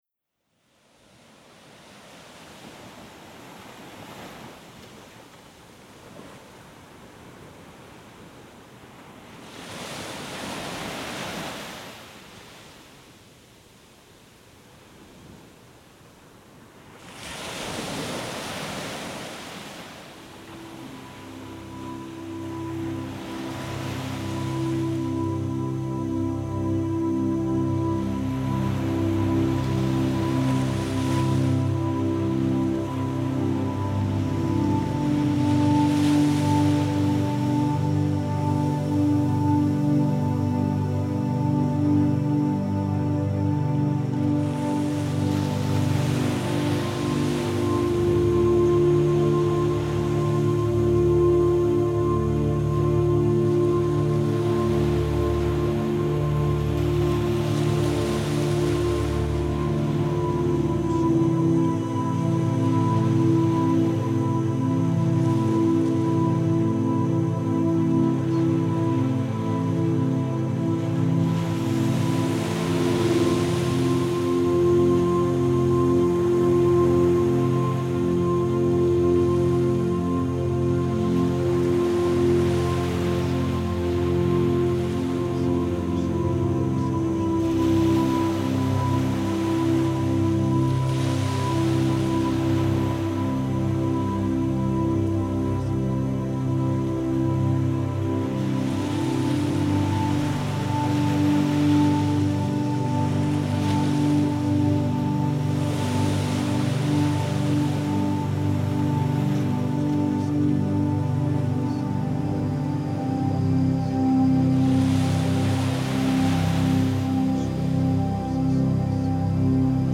INSTRUCTIONS Subliminal Activation sessions embed the mental programming script inside subliminal technology. This script is hidden behind relaxing music.
You may hear fragments of words from time to time.